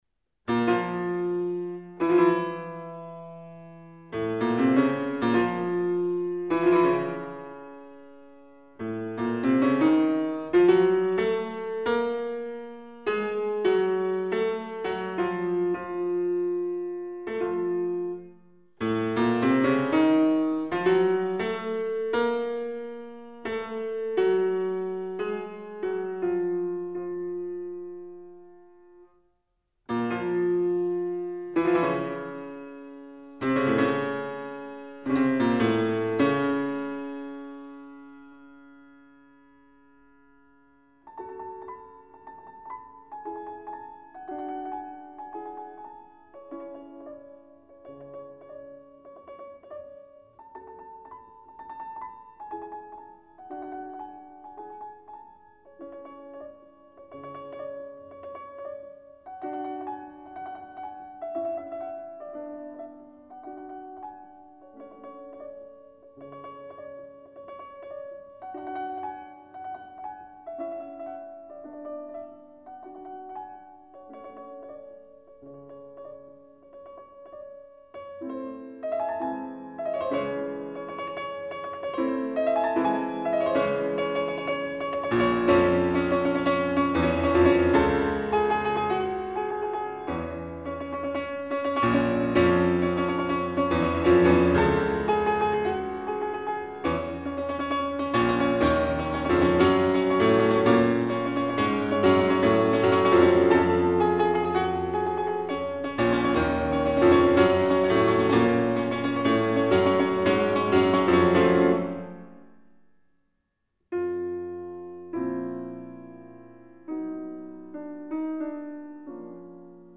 4. Breve melodia discendente "con dolore"
Goldenberg e Schmuyle (versione pianistica)